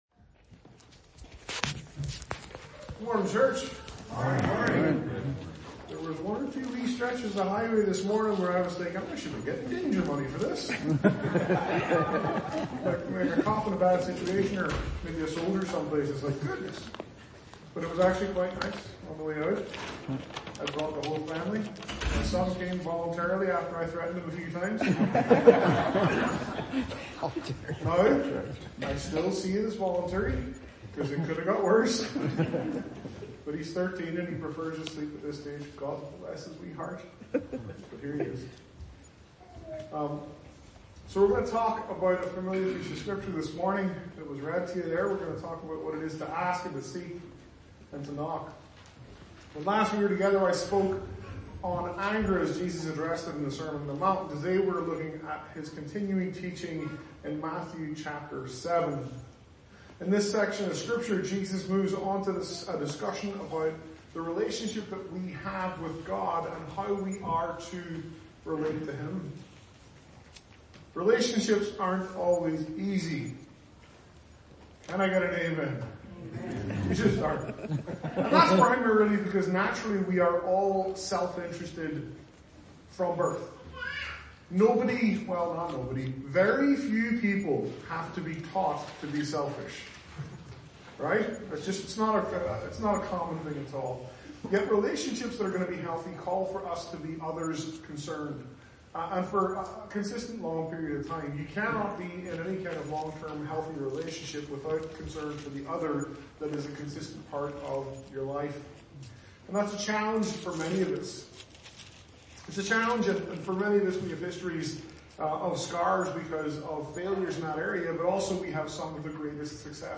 Sermons - Whiteshell Baptist Church